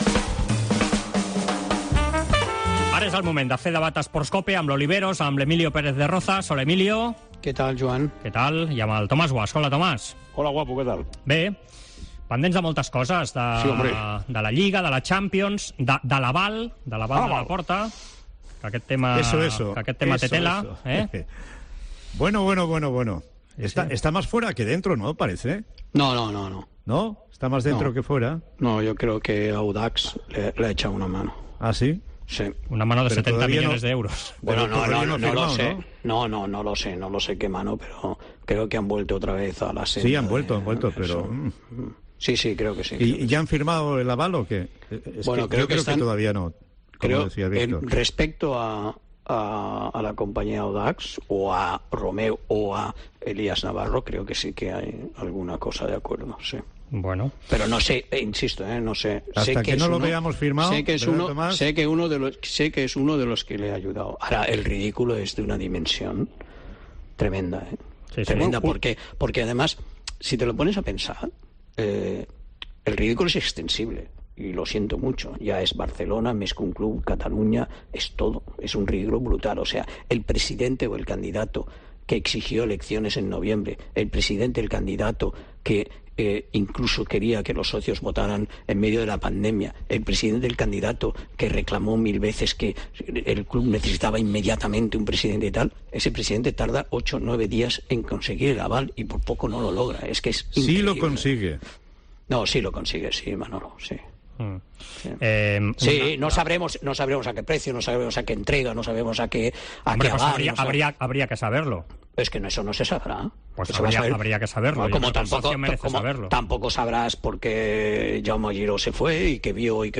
Escolta el 'Debat Esports COPE' amb Tomás Guasch i Emilio Pérez de Rozas analitzant les gestions per aconseguir l'aval necessari per presidir el FC...